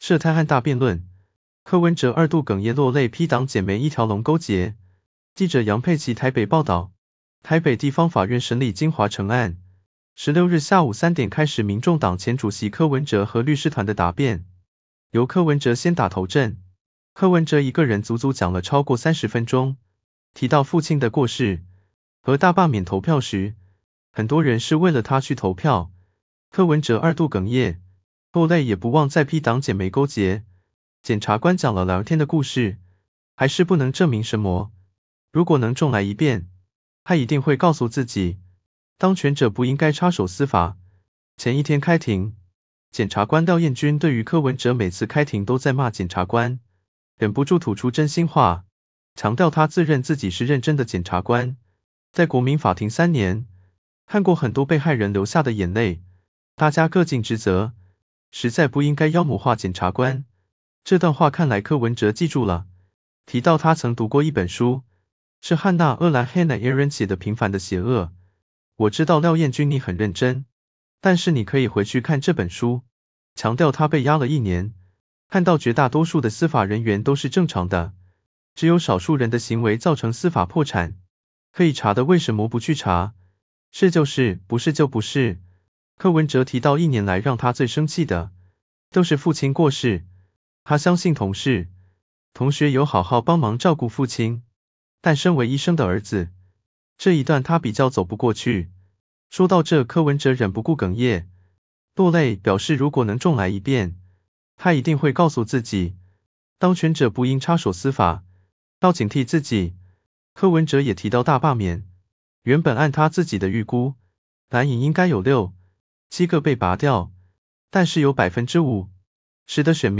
京華城案最終辯論，輪到柯文哲答辯，提到父親和支持者，2度哽咽落淚。
這讓柯文哲又再度哽咽，話停頓好幾次都說不出來。